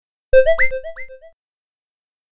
• Качество: 192, Stereo
короткие